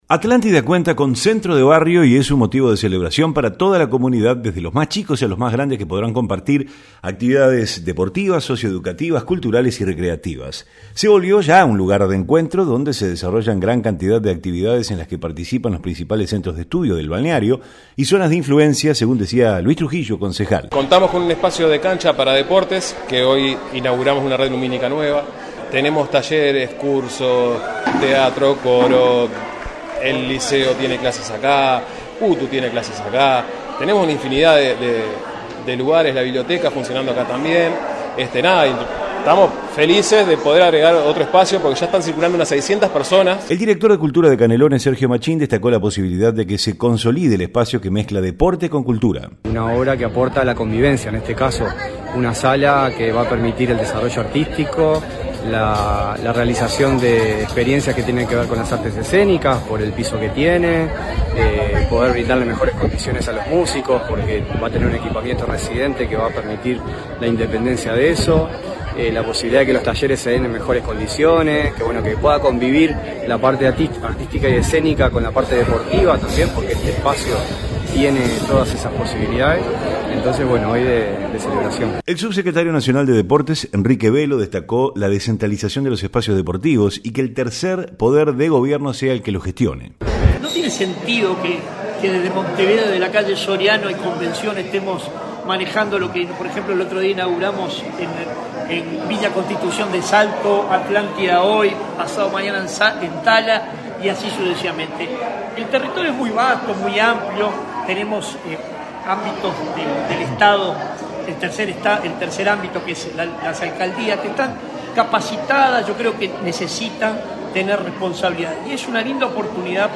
REPORTE-CENTRO-DE-BARRIO.mp3